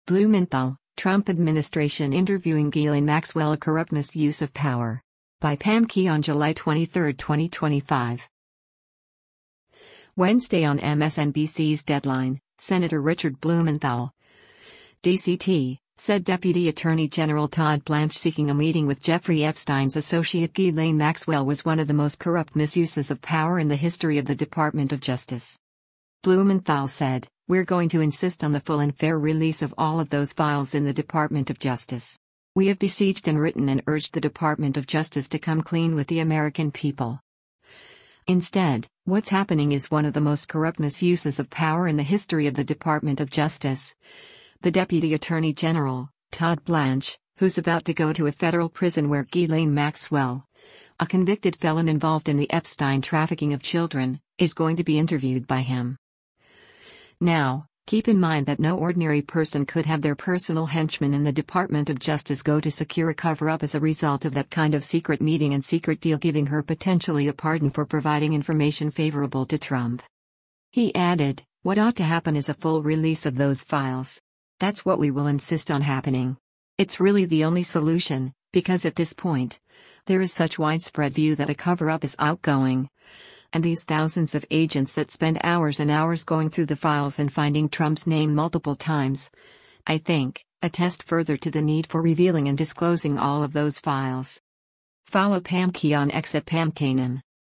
Wednesday on MSNBC’s “Deadline,” Sen. Richard Blumenthal (D-CT) said Deputy Attorney General Todd Blanche seeking a meeting with Jeffrey Epstein’s associate Ghislaine Maxwell was “one of the most corrupt misuses of power in the history of the Department of Justice.”